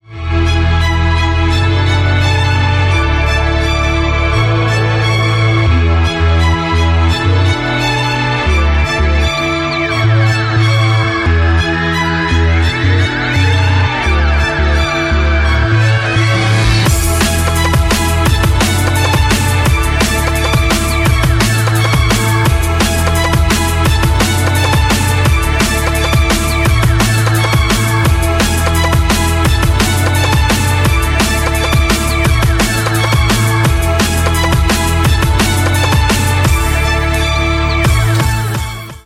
• Качество: 128, Stereo
поп
скрипка
Rock